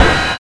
TRA07RIDE.wav